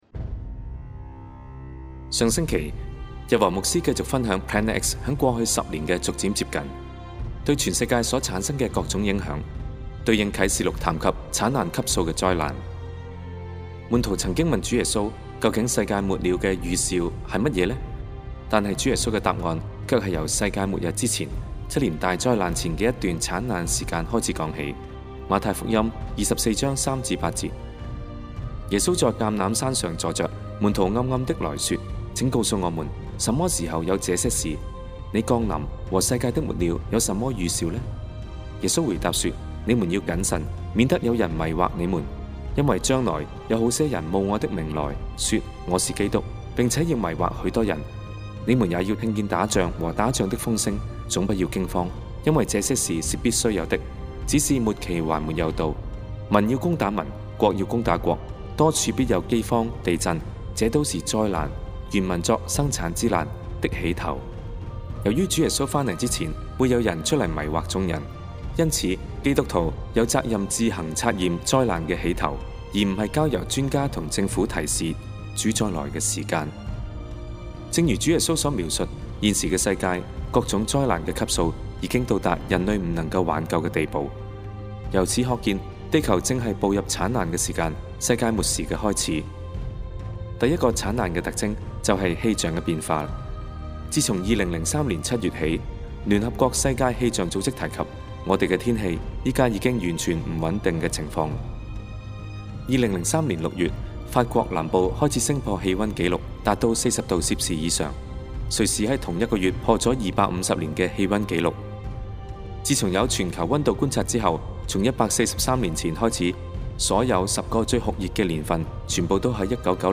錫安教會主日信息 : 2012 榮耀盼望 vol. 120 星際啟示錄 第二部份 (19) Planet X (7) Planet X再來的徵兆 (5)